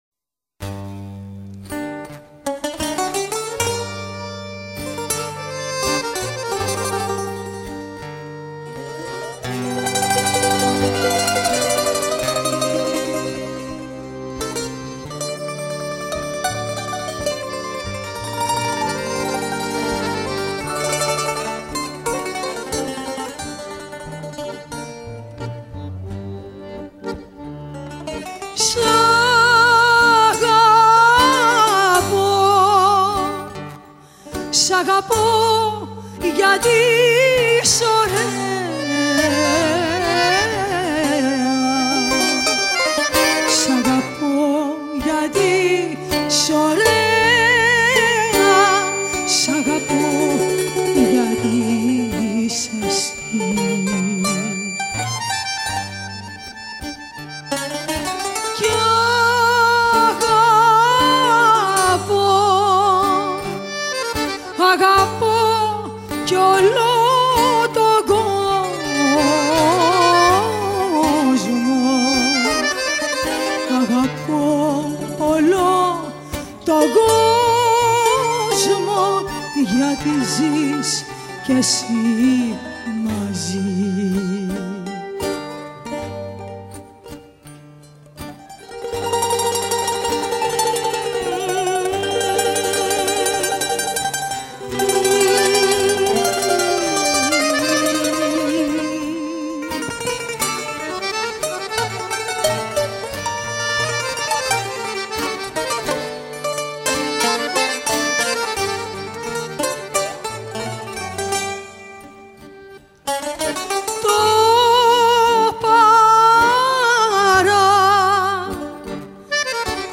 chant composé dans les années 1910